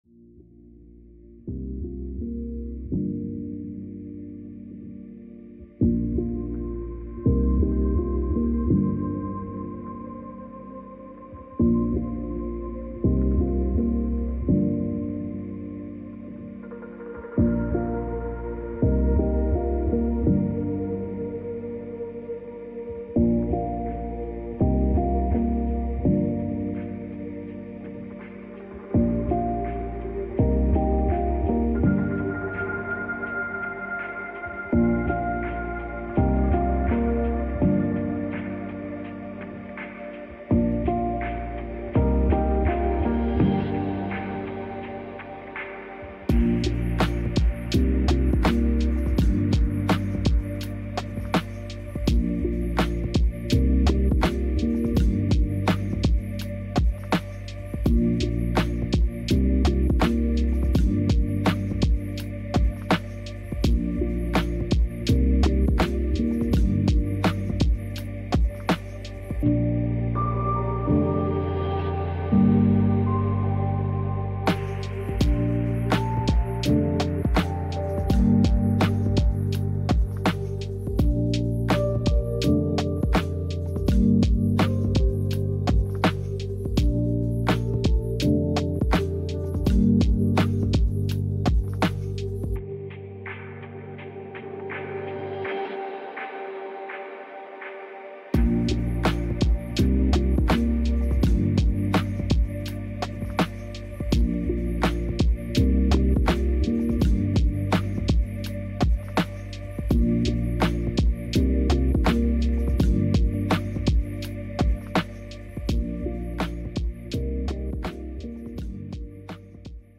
Nashville First Baptist Church